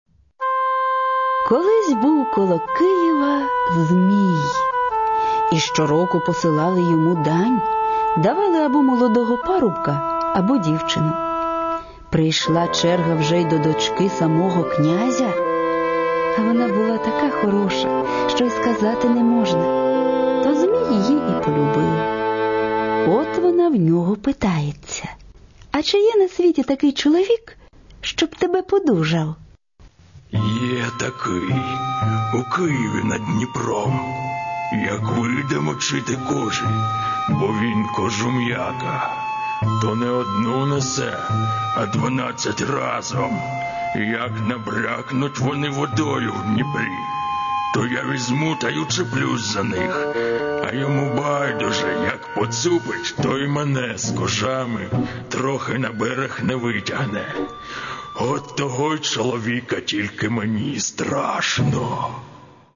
Аудио книги
Он содержит семь сказок, и каждую из них по-своему интересно слушать, ибо и прочитаны хорошо – не всякие родители так прочитают, не поленятся – и музычка здесь играет, и есть общая канва, рассказ, который подводит к каждой сказке отдельно.